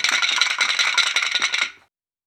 Shaker [2].wav